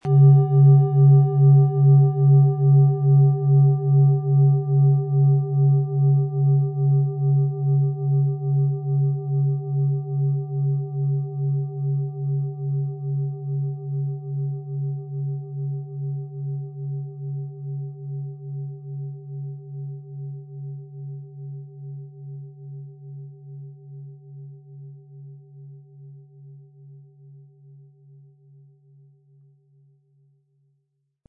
Planetenton 1
Um den Original-Klang genau dieser Schale zu hören, lassen Sie bitte den hinterlegten Sound abspielen.
Der gratis Klöppel lässt die Schale wohltuend erklingen.
MaterialBronze